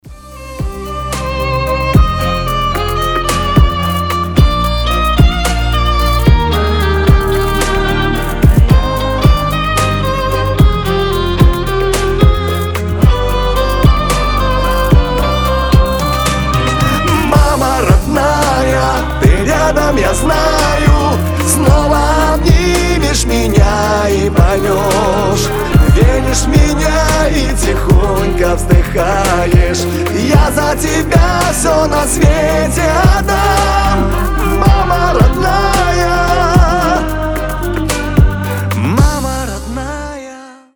• Качество: 320, Stereo
душевные
скрипка